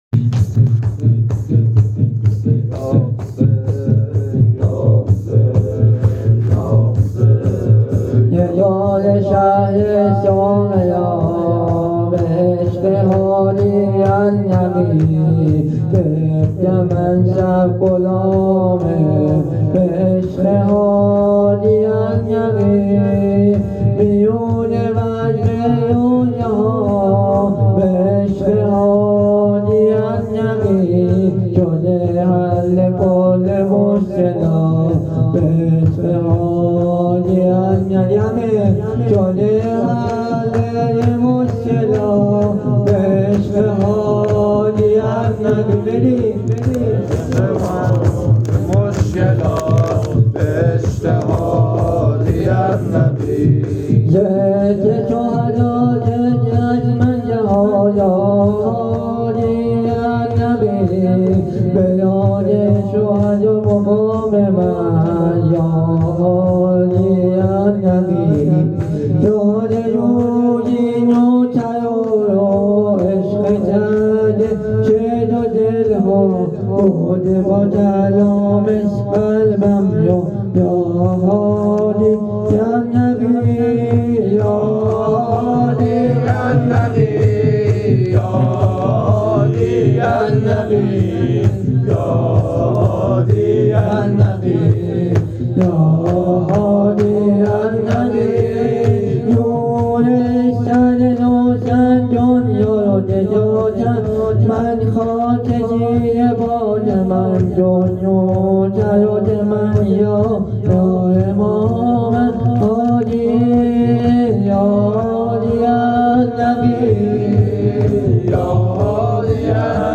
هیت روضه الزهرا تهران